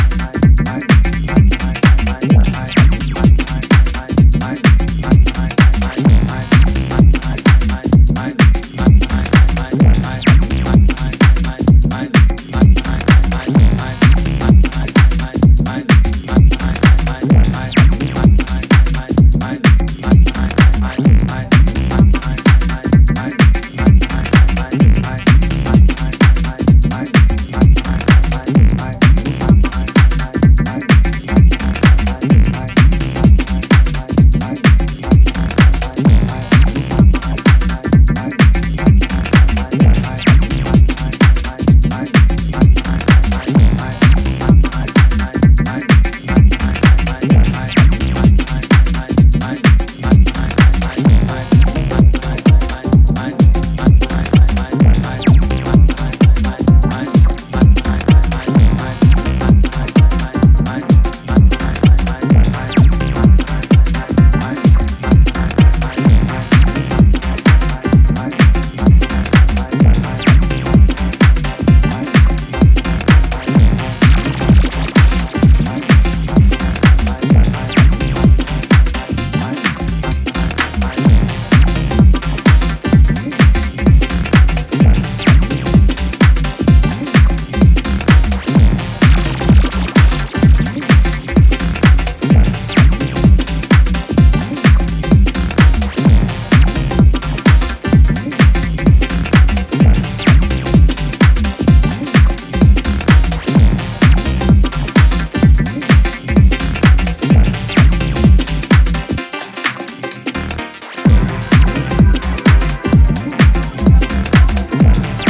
high-energy, retro-inspired journey through house and techno
Rooted in the uptempo energy of early ‘90s house and techno